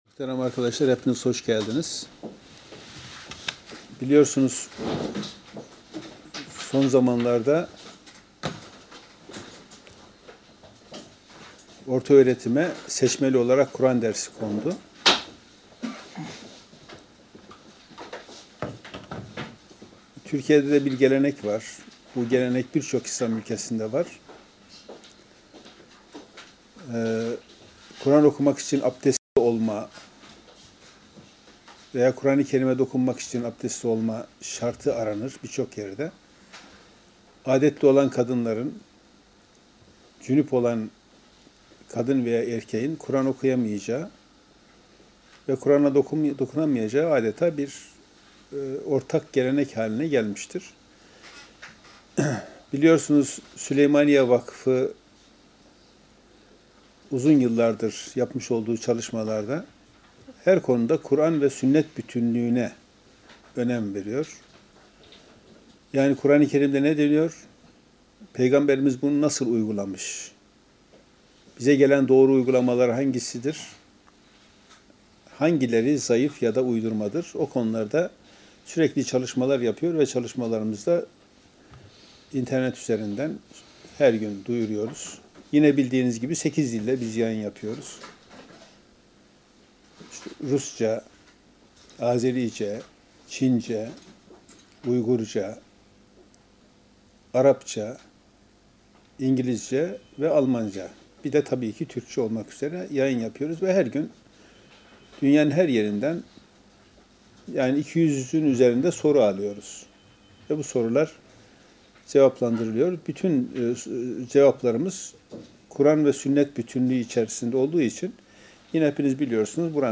Basın Toplantıları